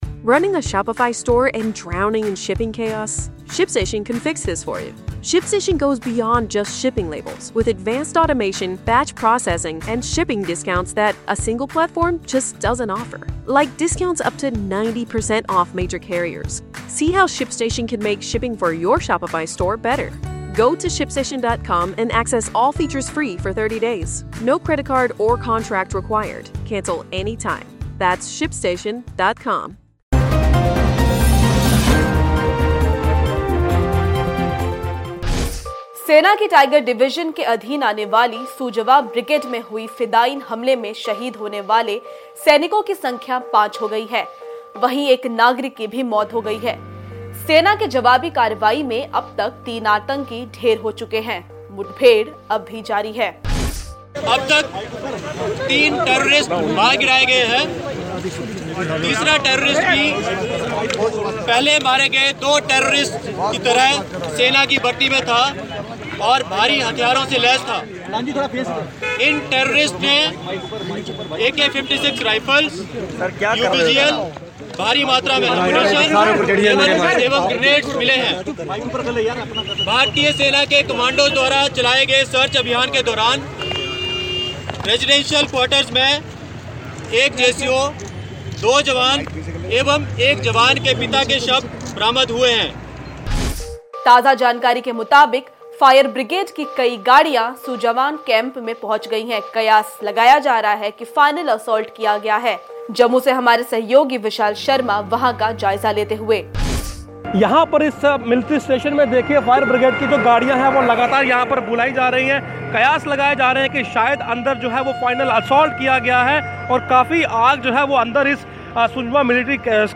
News Report / फायर ब्रिगेड की 4 गाड़िया और NIA सुनजवा मिलिट्री स्टेशन में हुई दाखिल